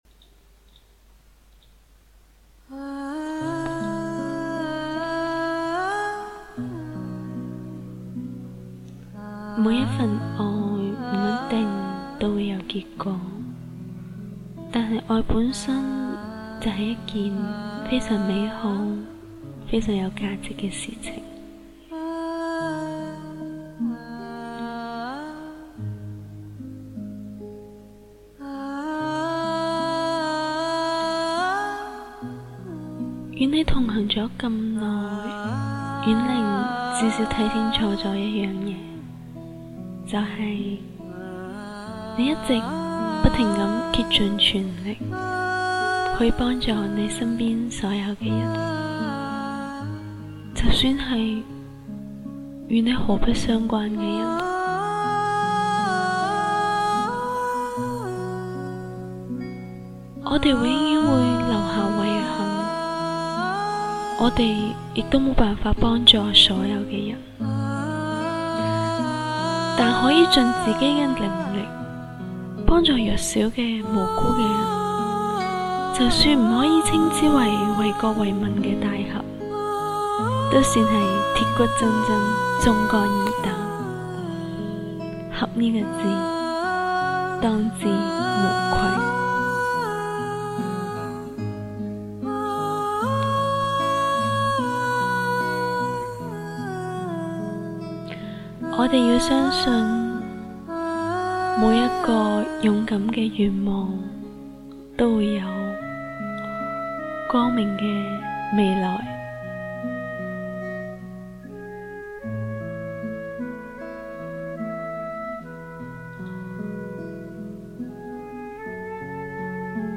方言恶搞篇：
粤语版：